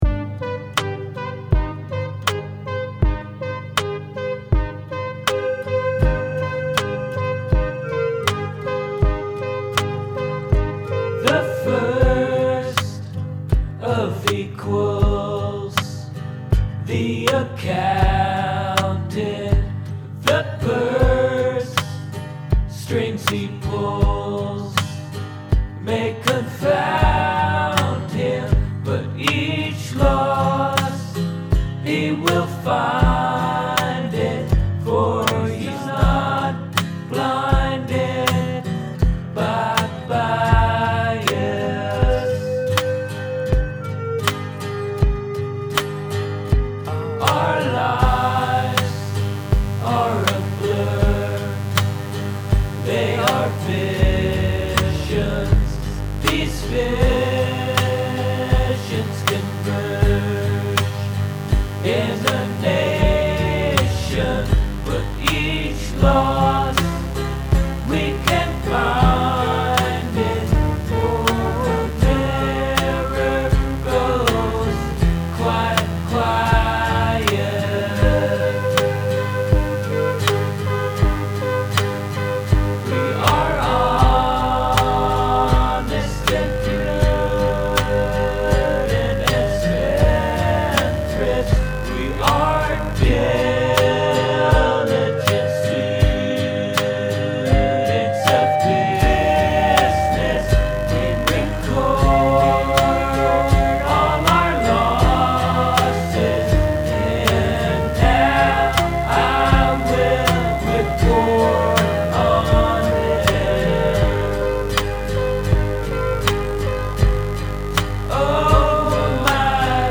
It's in C major, but i tuned my guitar down two whole steps for this recording.
Verse: C, G, C, G, C, F, Am, G, F, Am, G, C
verse, verse, verse, chorus
The vocals are way too low.